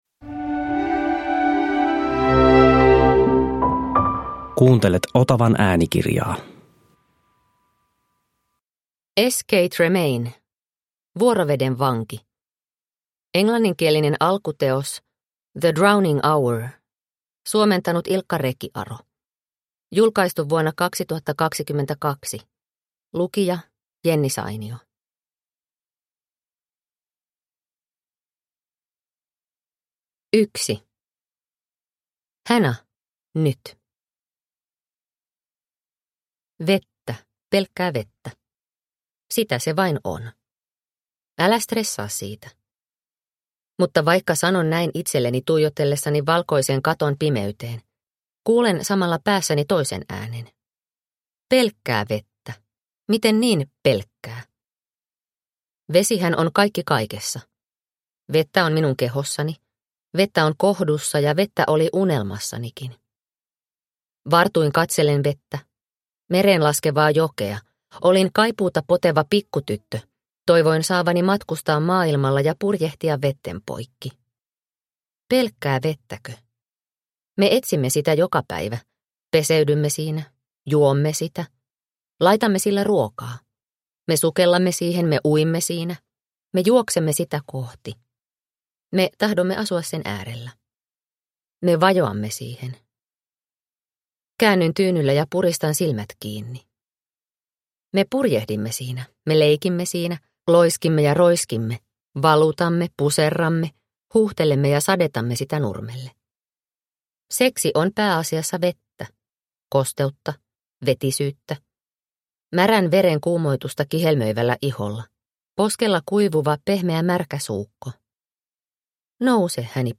Vuoroveden vanki – Ljudbok – Laddas ner